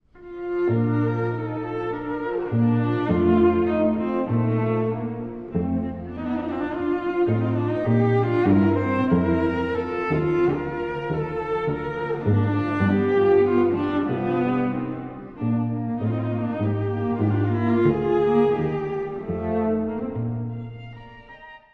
牧歌的で心が惹かれる最終楽章。
しかし、中盤、後半には感情の高まりも現れます。
1楽章と同じく、内声の波に乗ってチェロ・バイオリンが歌い上げるさまが素敵です。